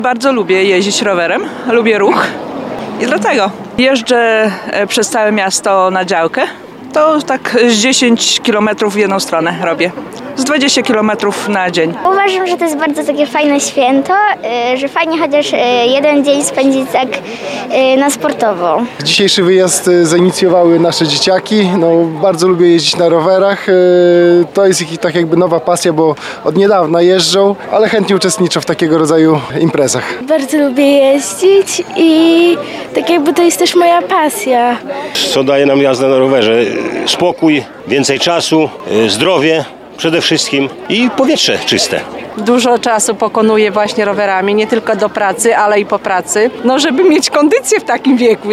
Jak przyznali w rozmowie z Radiem 5, aktywność fizyczna jest bardzo ważna, a ruch wpływa pozytywnie na zdrowie.
rowerzysci.mp3